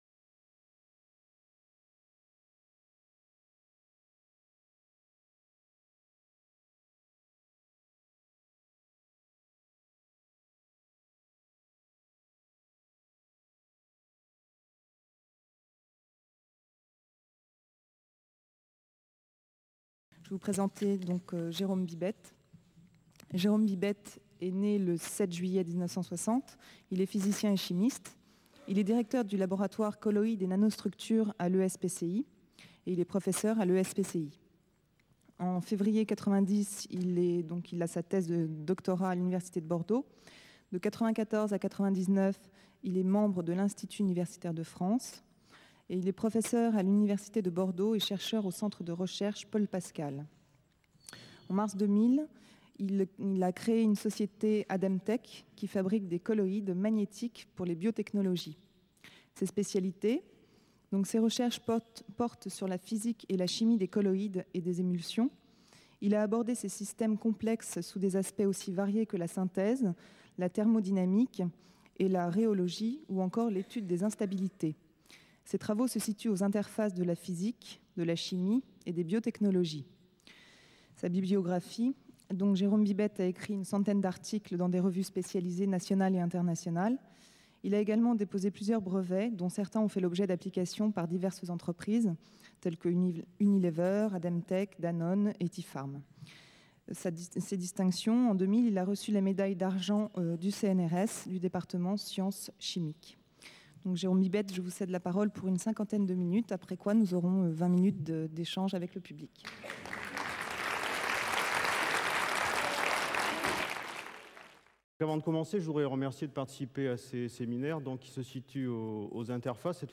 L'exposé introduit lutilisation des colloïdes dans le domaine du diagnostic biologique.